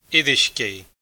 Pronunciation[ˈeɾʲiʃkʲej]